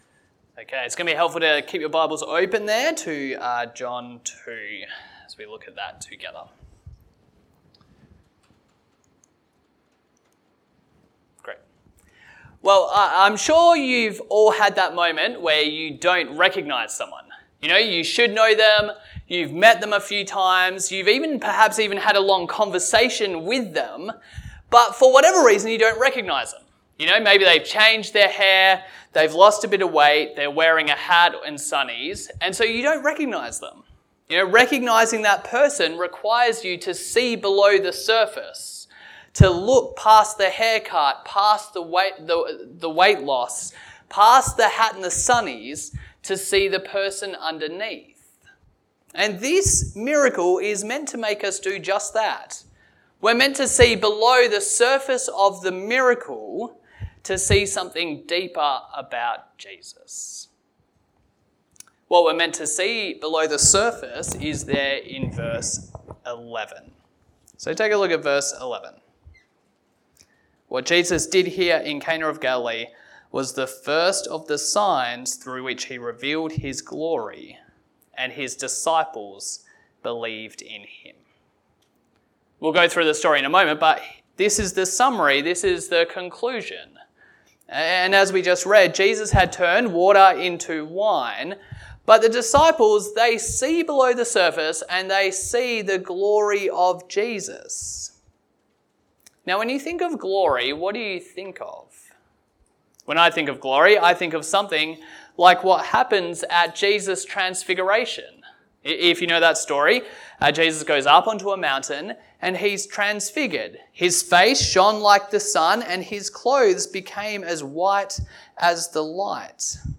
A message from the series "One Off Sermons."